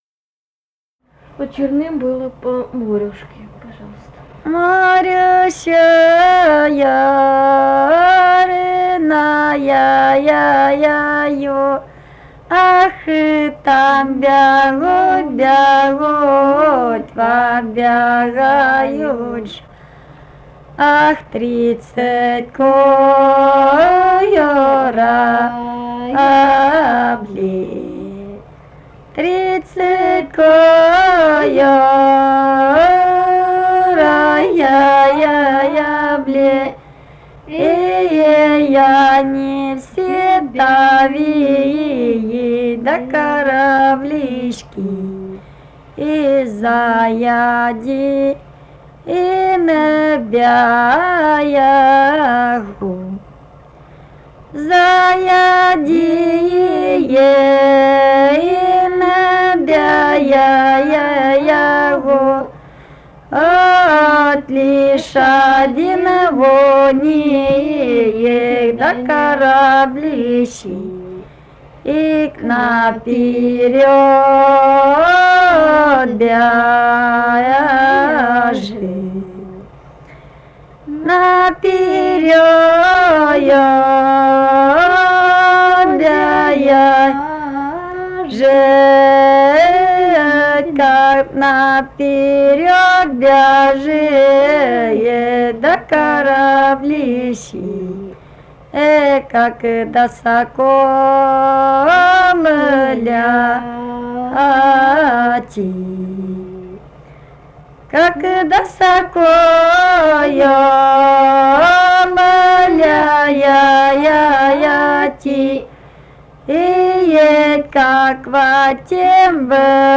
Этномузыкологические исследования и полевые материалы
Ставропольский край, пос. Новокумский Левокумского района, 1963 г. И0726-08]]